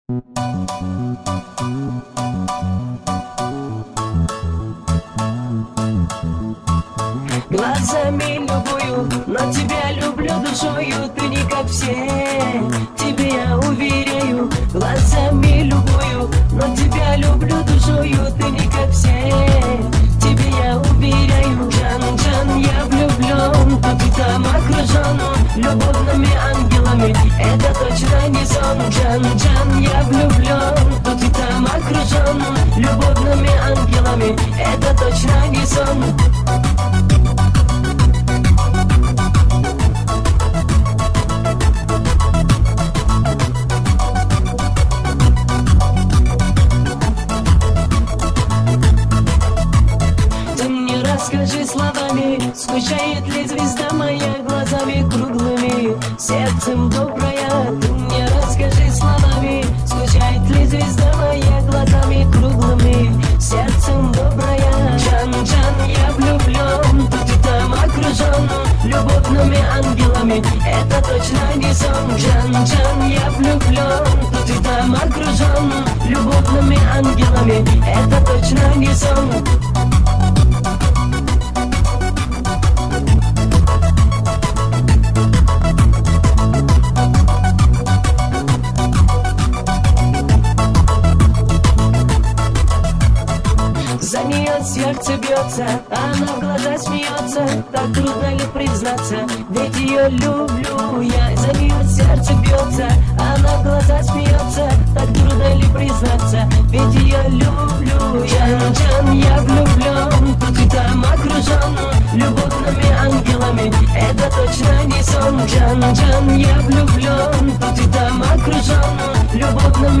Категория: Эстрада